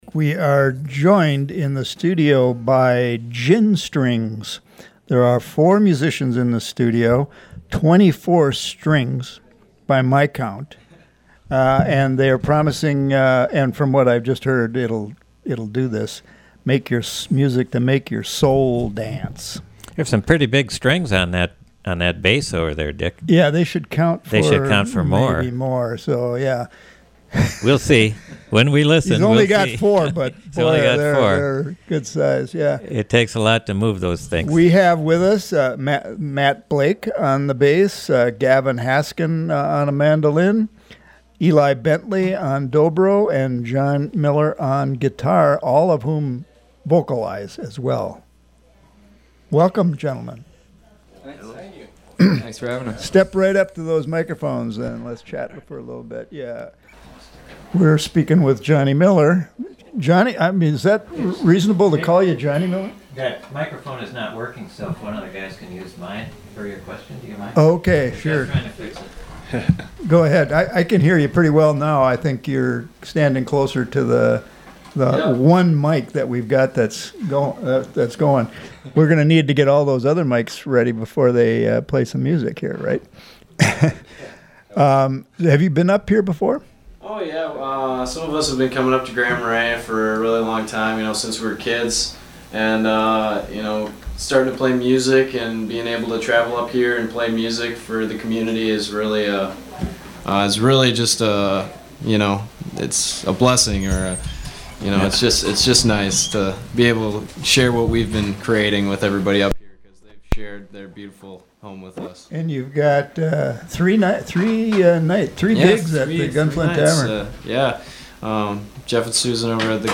fast-paced music with wonderful harmony
bass
mandolin
dobro
guitar.